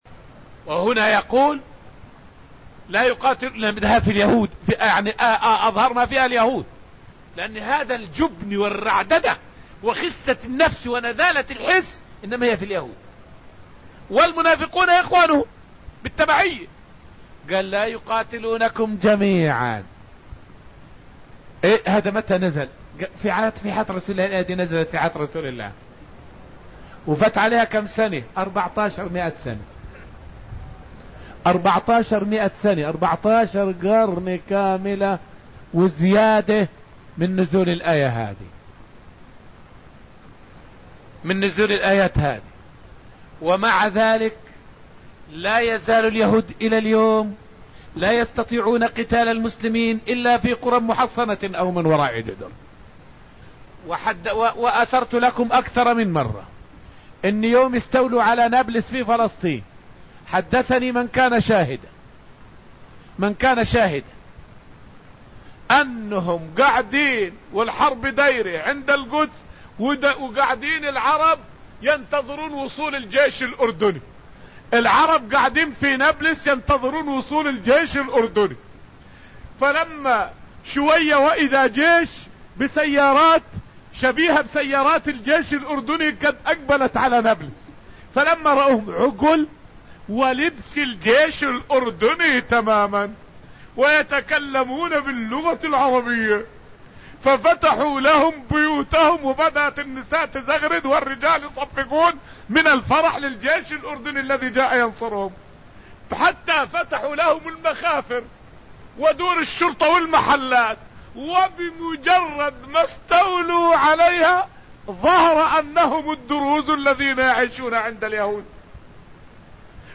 فائدة من الدرس التاسع من دروس تفسير سورة الحشر والتي ألقيت في المسجد النبوي الشريف حول الإعجاز الغيبي في وصف قتال اليهود من وراء الجُدُر.